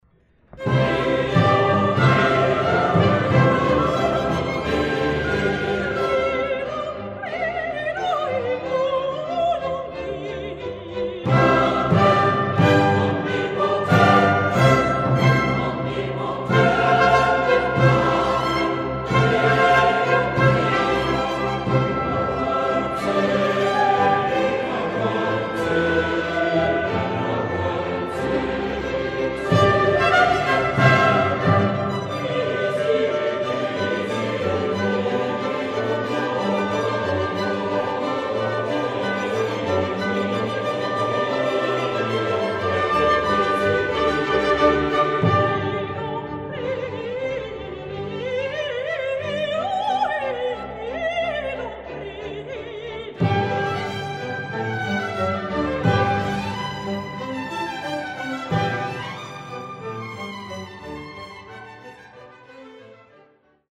Chor und Orchester von St. Peter